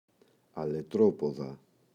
αλετροπόδα, η [aletroꞋpoða]